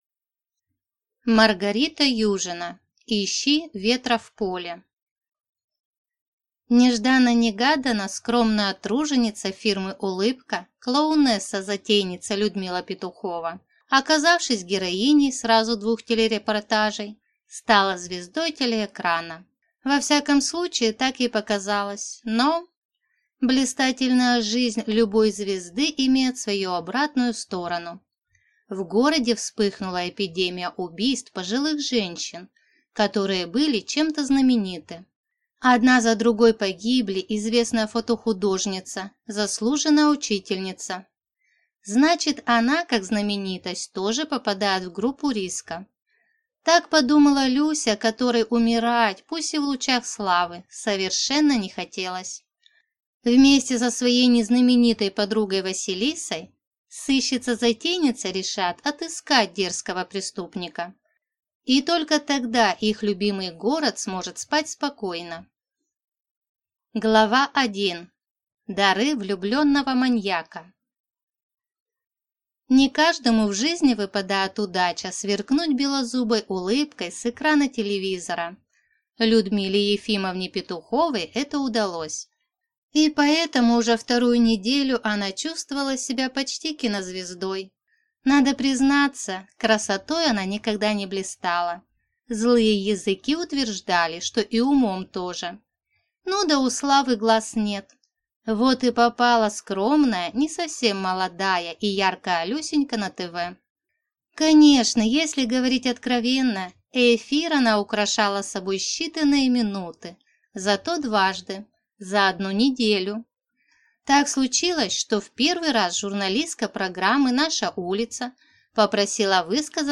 Аудиокнига Ищи ветра в поле | Библиотека аудиокниг